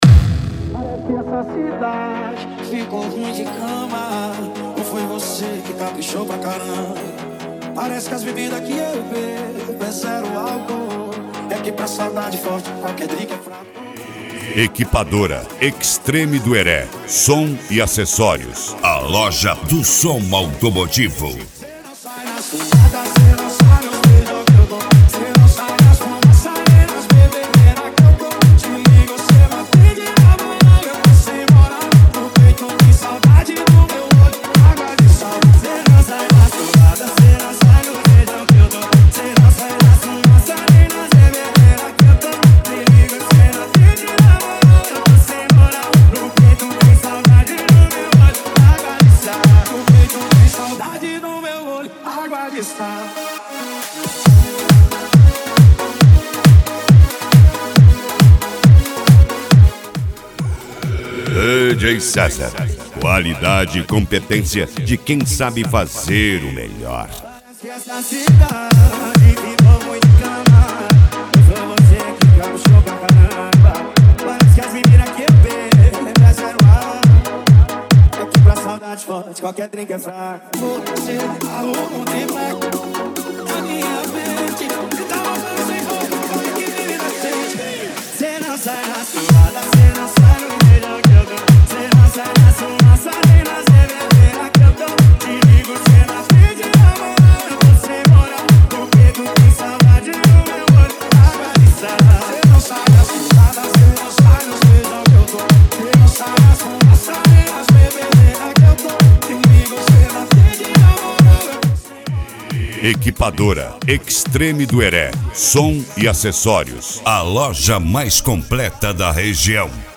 Deep House
Funk
Mega Funk
SERTANEJO